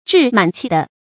志滿氣得 注音： ㄓㄧˋ ㄇㄢˇ ㄑㄧˋ ˙ㄉㄜ 讀音讀法： 意思解釋： 猶志得意滿。